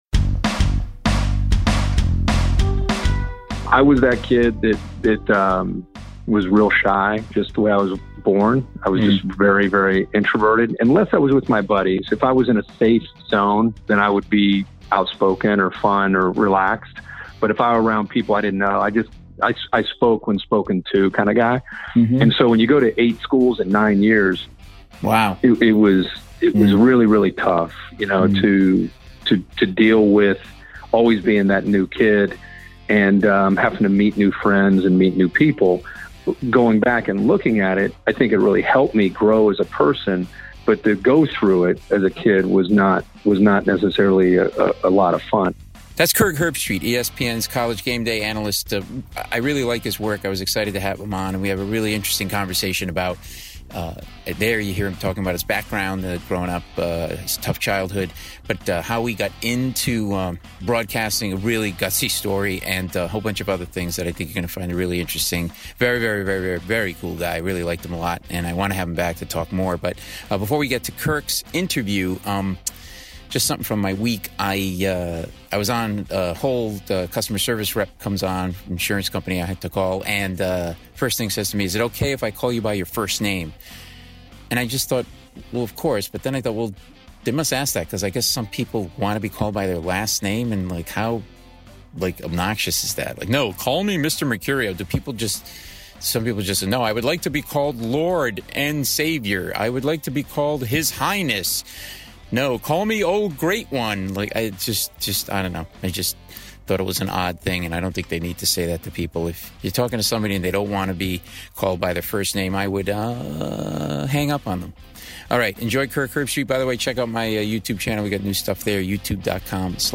Kirk Herbstreit - Emmy Winning Analyst - ESPN College Game Day (Paul Mecurio interviews Kirk Herbstreit; 30 Aug 2021) | Padverb